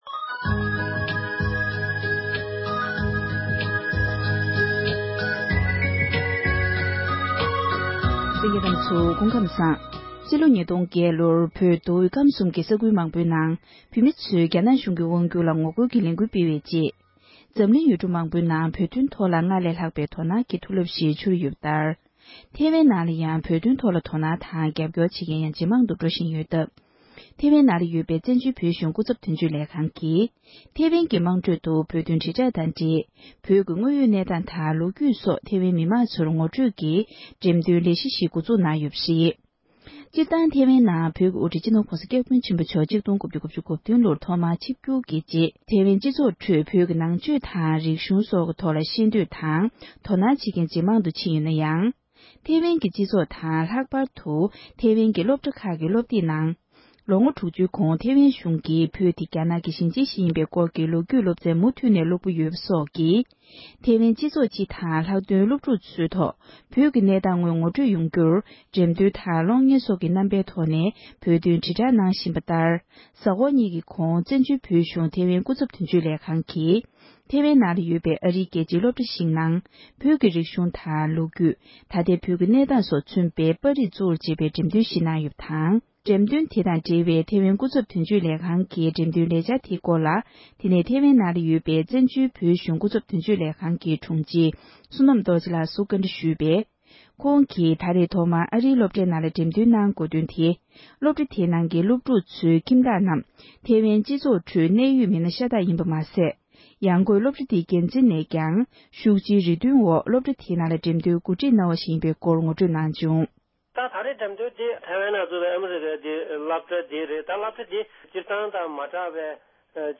བཀའ་འདྲི་ཞུས་པ་ཞིག་གསན་རོགས༎